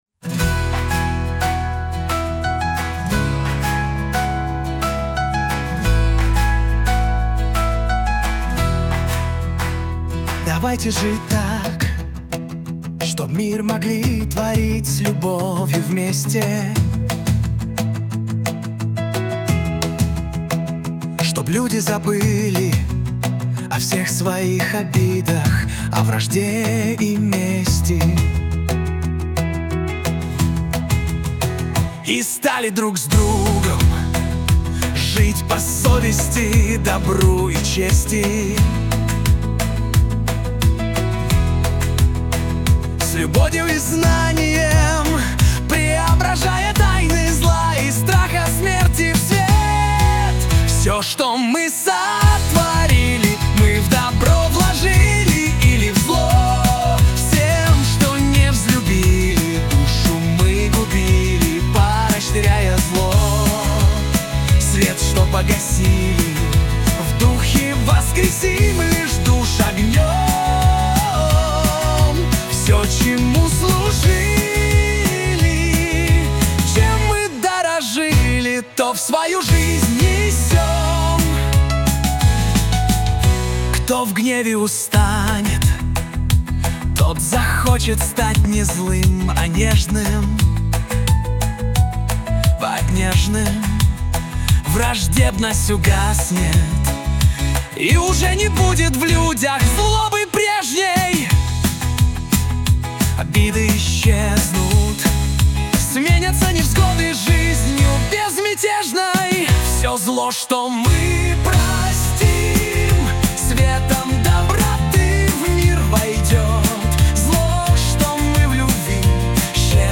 кавер-врсия